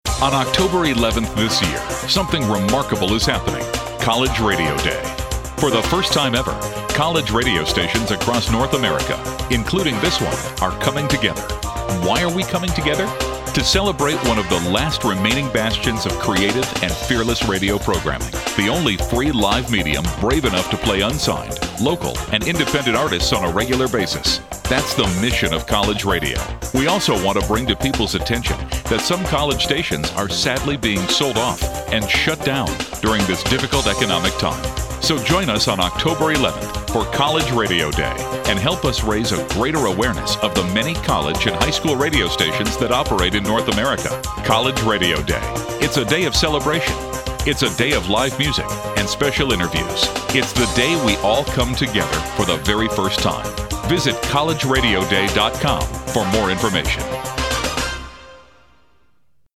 College Radio Day bumper, put together by yours truly. I used the dry voice they had provided and an old Prevue Channel song, and they just worked perfectly together.